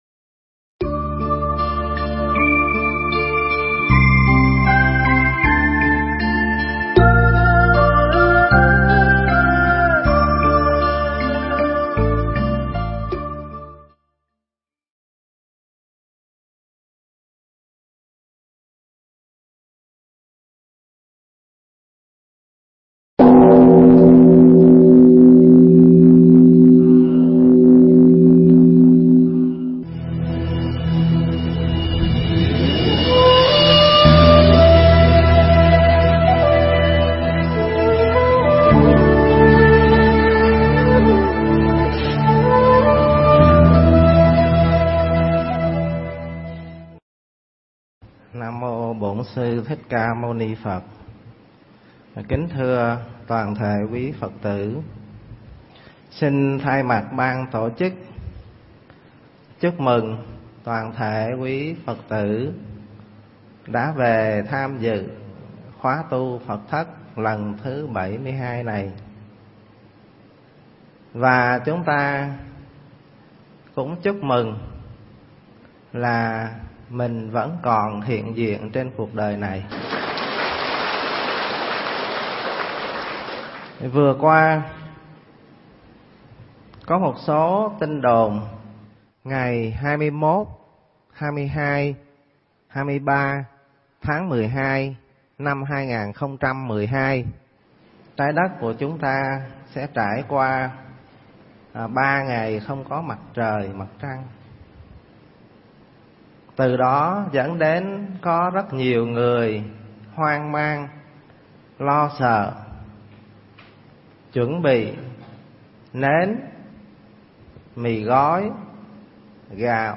Nghe Mp3 thuyết pháp Ngày Mai Nếu
Mp3 pháp thoại Ngày Mai Nếu